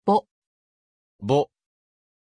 Pronunciation of Bo
pronunciation-bo-ja.mp3